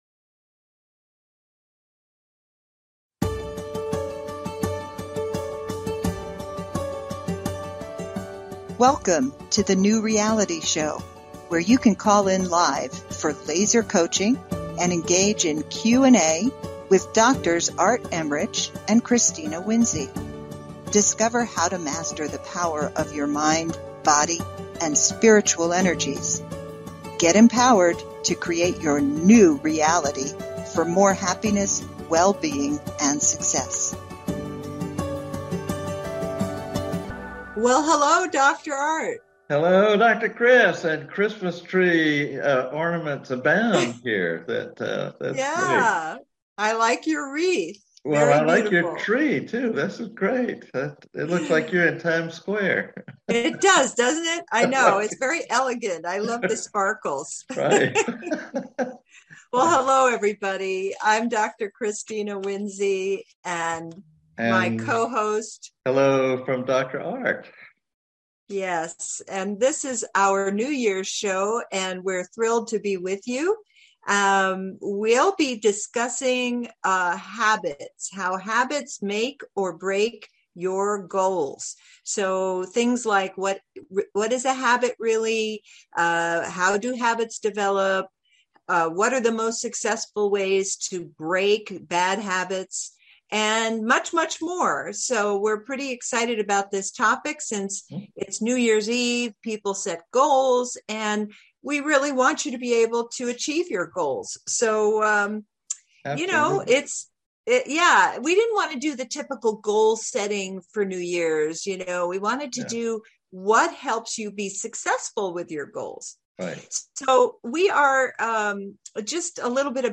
Headlined Show, New Reality Solutions December 31, 2021